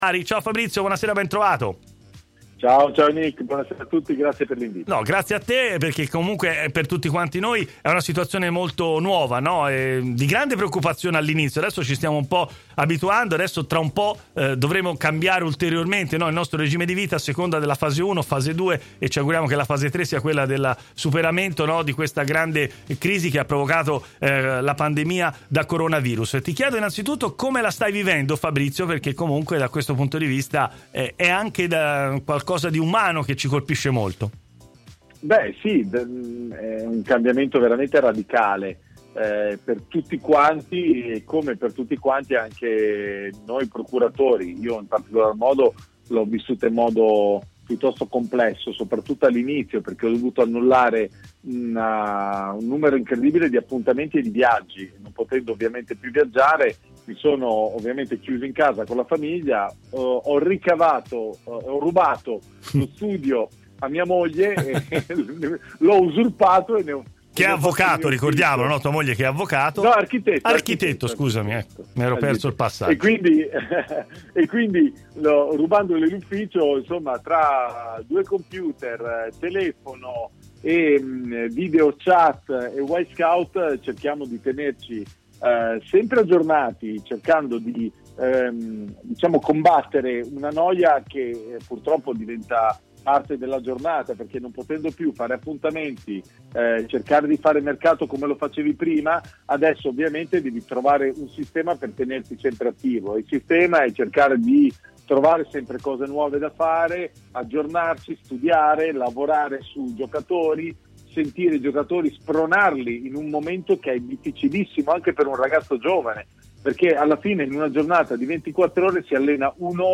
intervenuto in diretta nel corso di Stadio Aperto, trasmissione in onda.